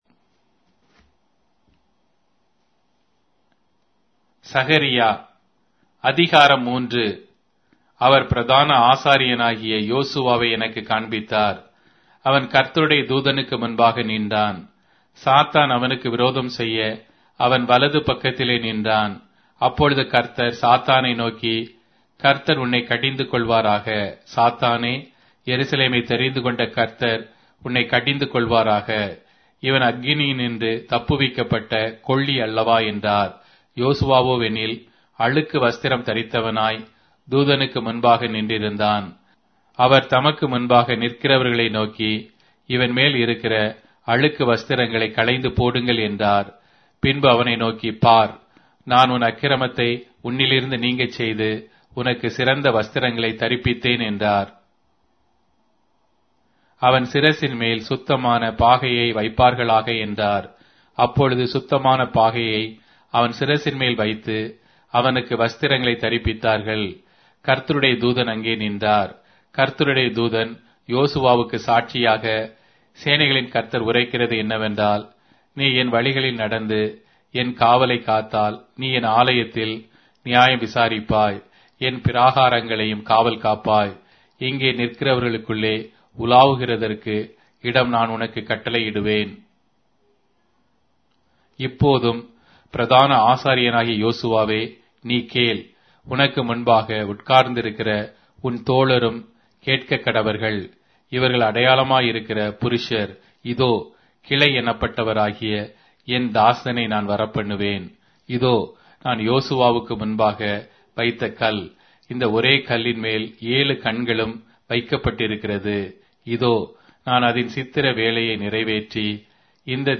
Tamil Audio Bible - Zechariah 4 in Web bible version